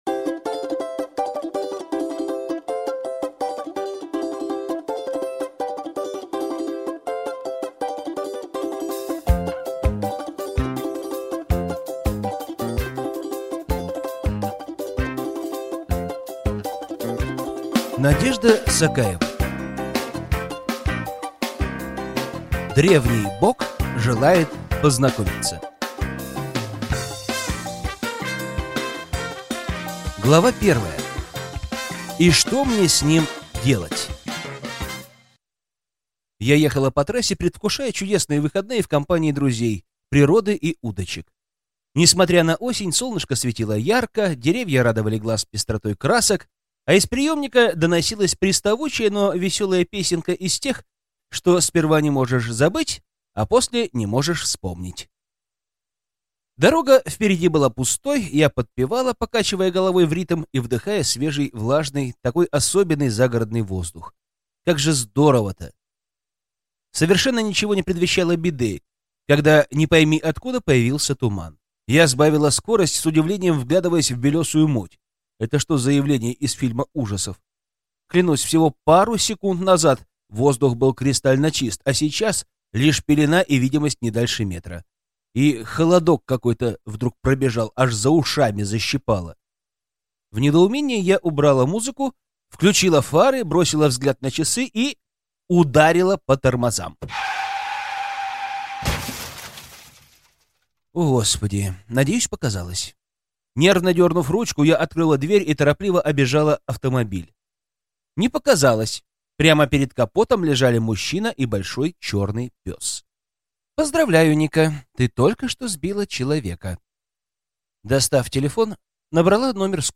Аудиокнига Древний бог желает познакомиться | Библиотека аудиокниг